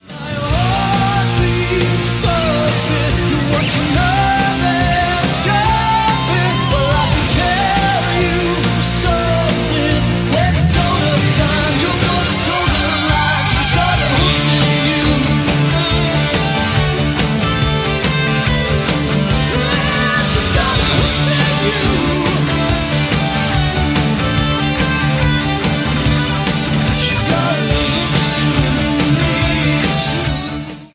It is of a lower quality than the original recording.